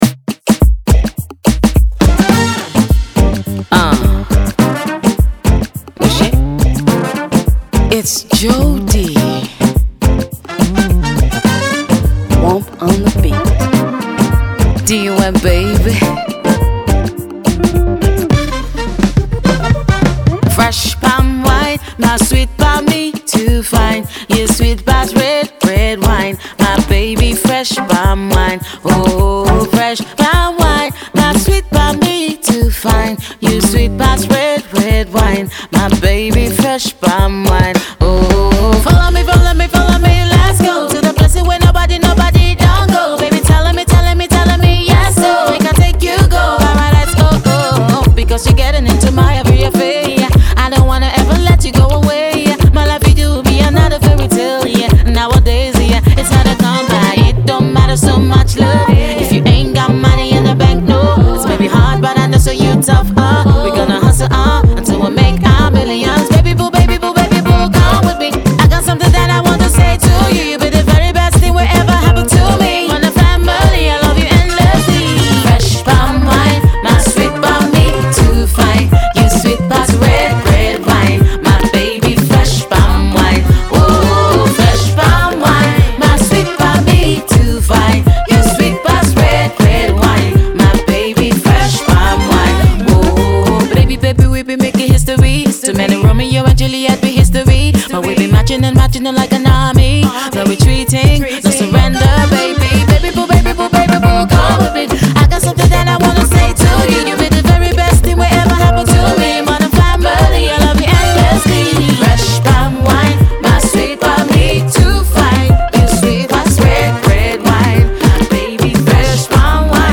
Afro pop sensation
returns with an afro beat classic